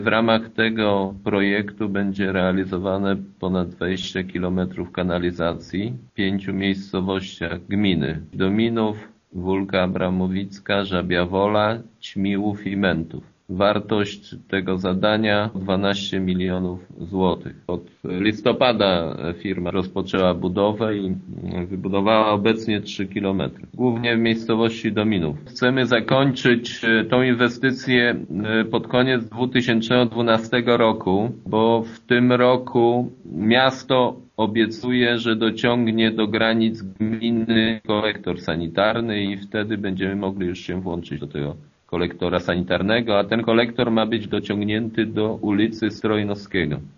„Pierwszy projekt dotyczy wyłącznie budowy sieci sanitarnej i jest współfinansowany z krajowego Programu Operacyjnego Infrastruktura i Środowisko. Jego realizacja pozwoli na podłączeni gminnej kanalizacji do sieci miejskiej w Lublinie ” - wyjaśnia wójt Jacek Anasiewicz: